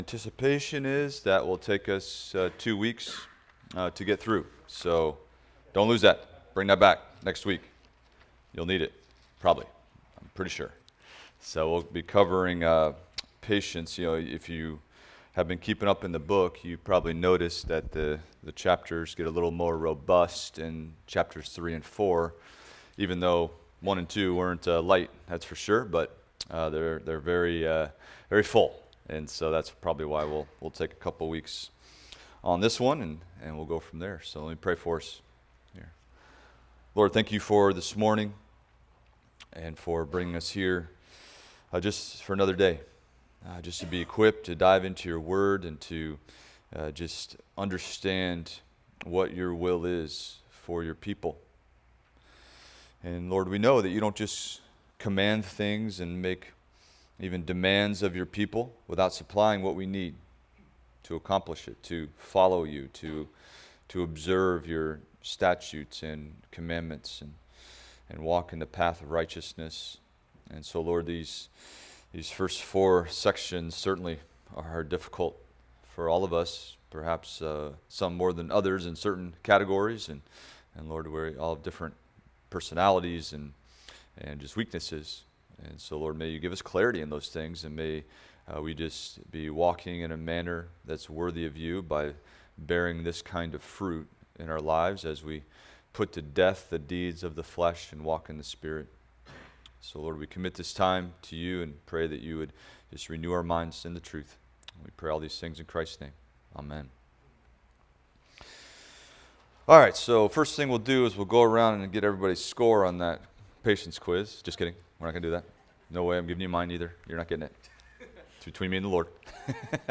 Adult Sunday School – Resolving Conflict – Week 3